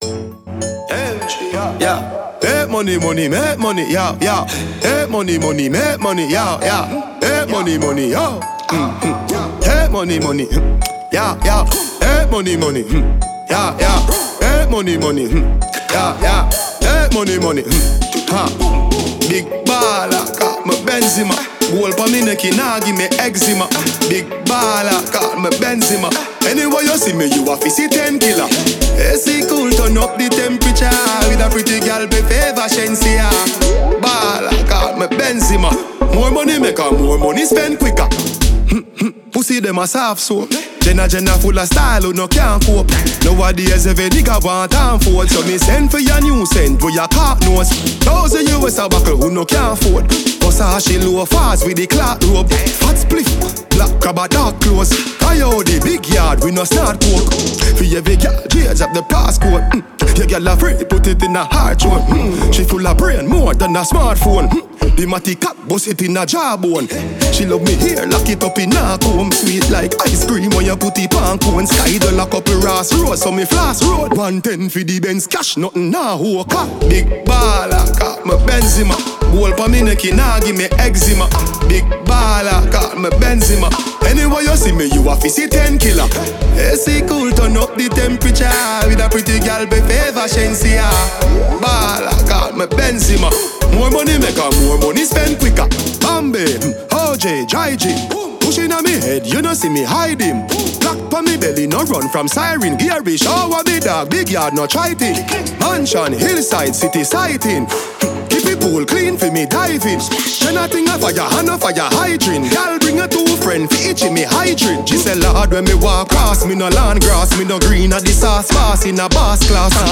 Genre: Telugu